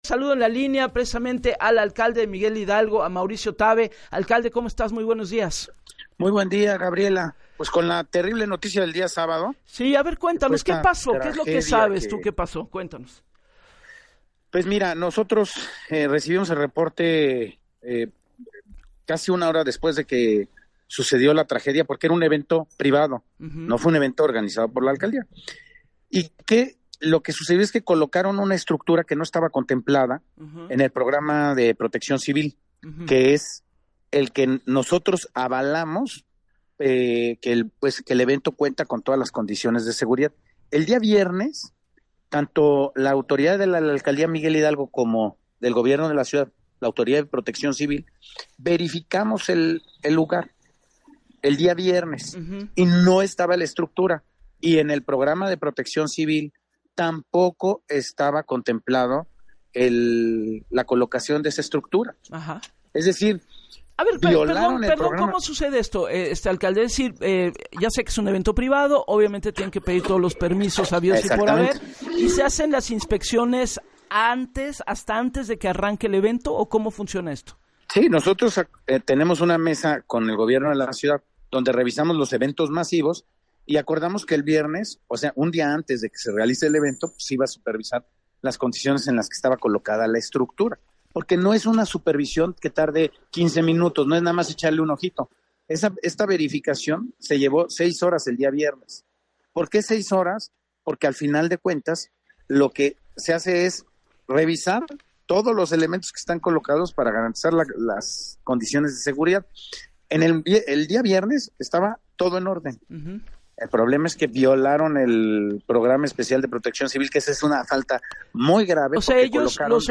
En entrevista para “Así las Cosas” con Gabriela Warkentin, el alcalde aseguró que los organizadores del evento “Van a tener que asumir la responsabilidad de lo que está pasando”.